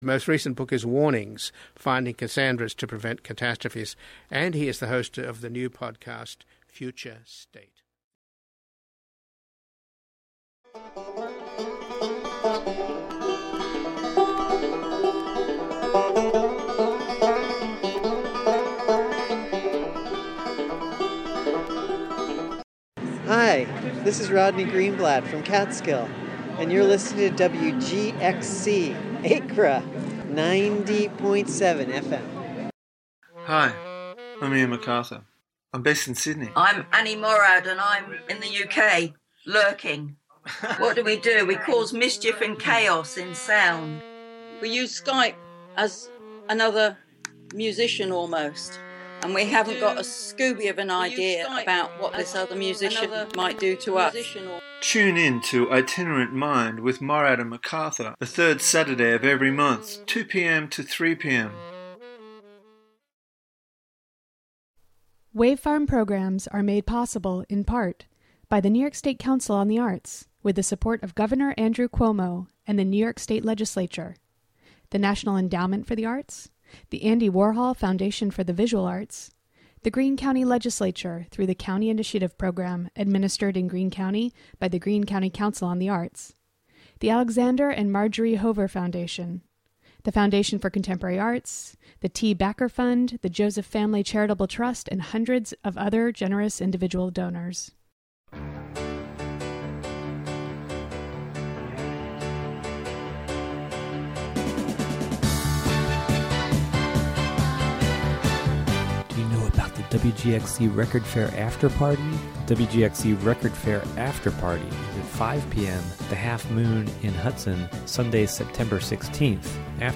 "All Together Now!" is a daily news show brought to you by WGXC-FM in Greene and Columbia counties.